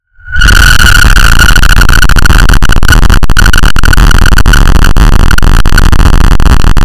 unnamed loud sound (WARNING FOR HEADPHONE USERS)
earrape effect loud meme noise sound sound effect free sound royalty free Sound Effects